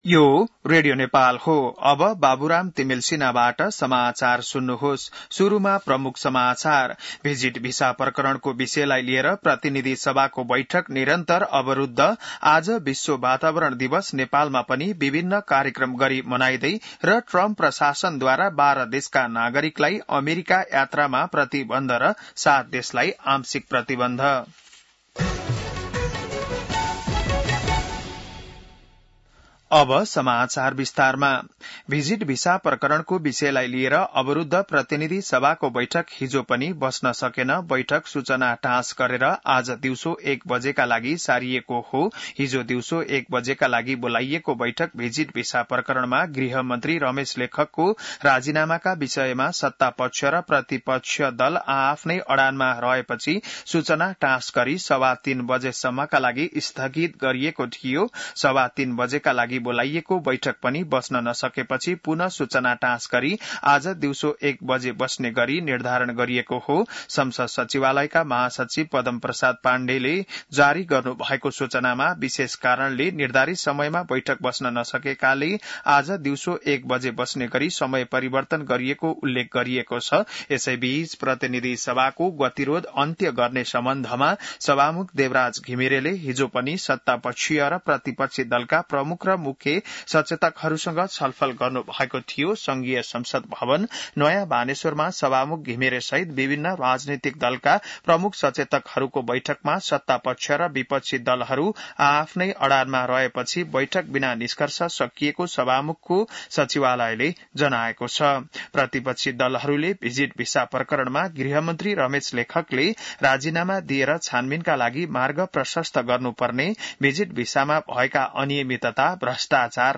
बिहान ९ बजेको नेपाली समाचार : २२ जेठ , २०८२